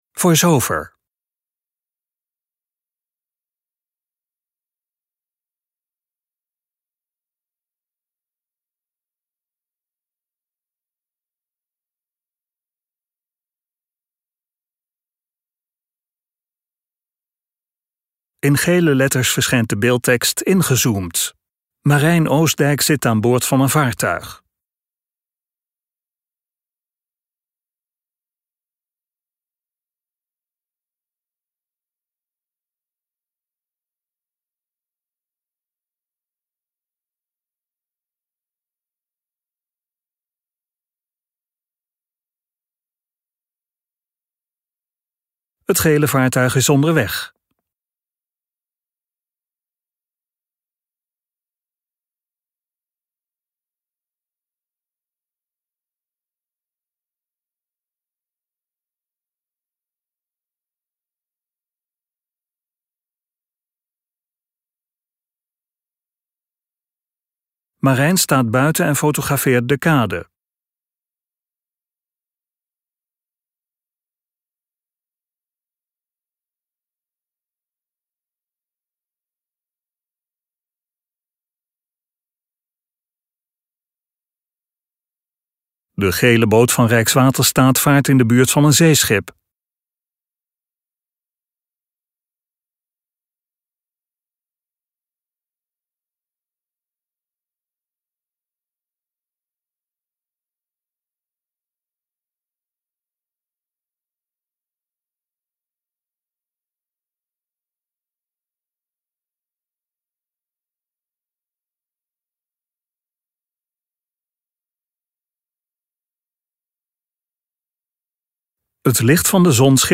In deze podcastaflevering varen we een dag mee met een van onze mobiele verkeersleiders.